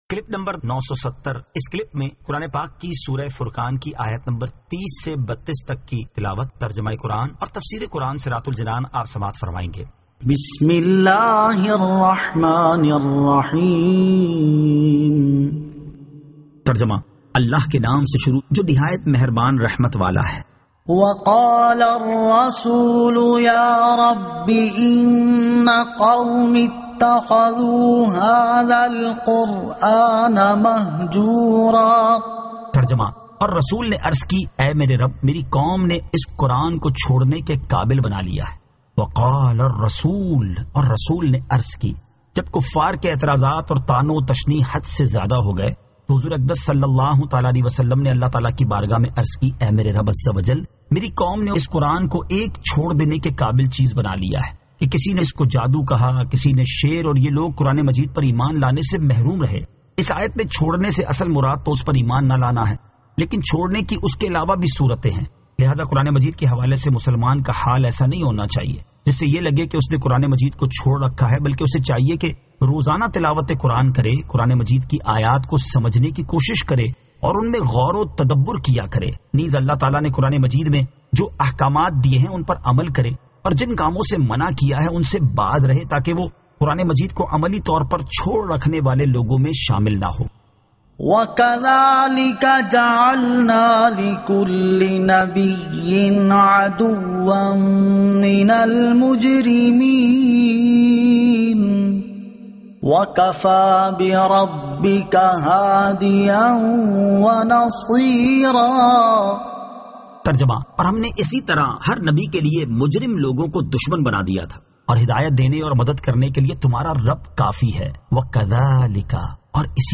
Surah Al-Furqan 30 To 32 Tilawat , Tarjama , Tafseer